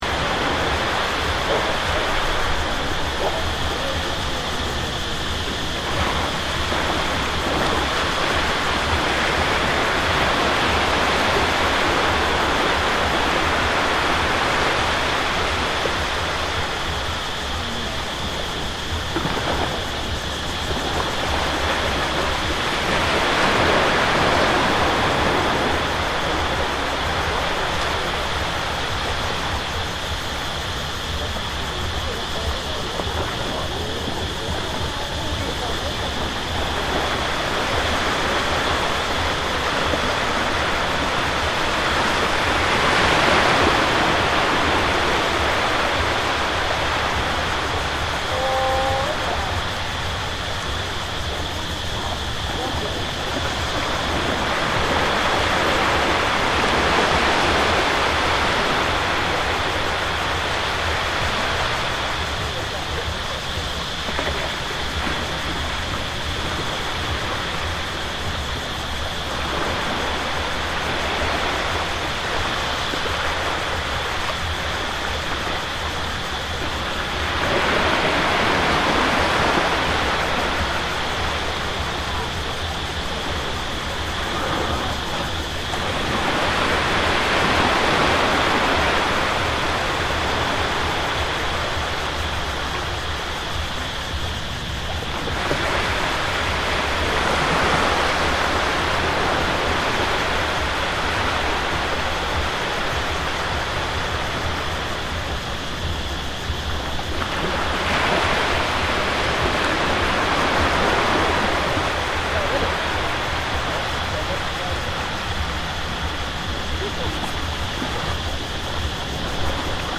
This recording takes place at Charlie Beach on Koh Mook, Thailand during the sunset hour. Listen to the combination of waves hitting the shore, the chatter of people and cicadas buzzing as the day comes to an end.